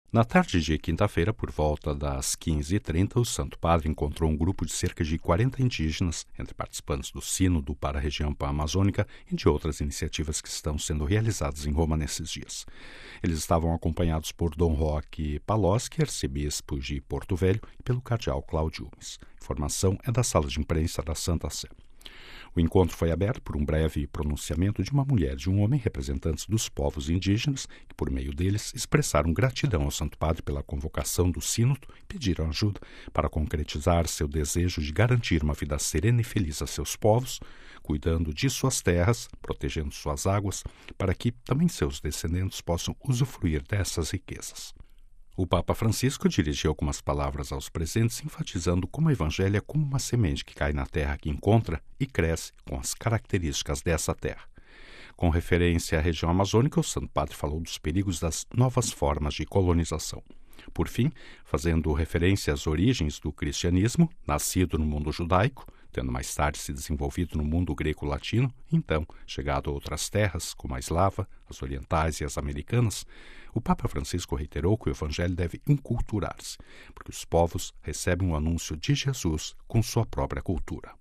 O encontro foi aberto por um breve pronunciamento de uma mulher e um homem, representantes dos povos indígenas, que por meio deles expressaram gratidão ao Santo Padre pela convocação do Sínodo e pediram ajuda para concretizar seu desejo de garantir uma vida serena e feliz a seus povos, cuidando de suas terras, protegendo suas águas, para que também seus descendentes possam usufruir destas riquezas.